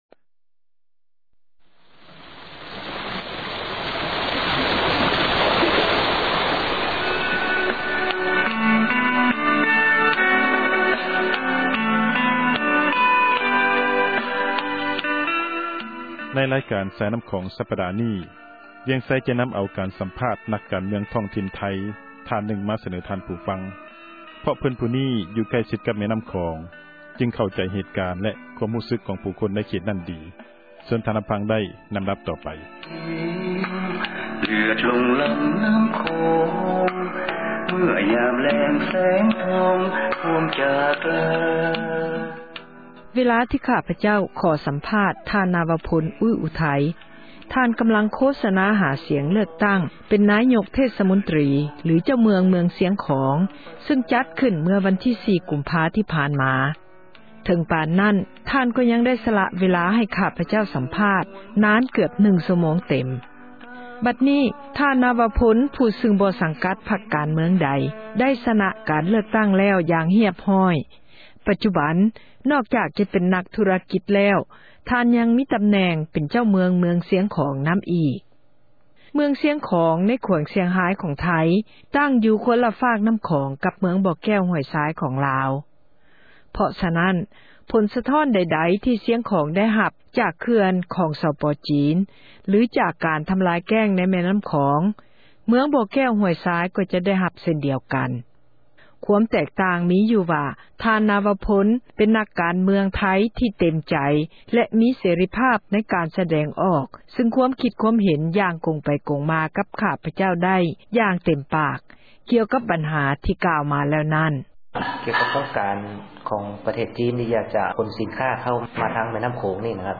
ສາຍນໍ້າຂອງ: ຕອນທີ 7 — ຂ່າວລາວ ວິທຍຸເອເຊັຽເສຣີ ພາສາລາວ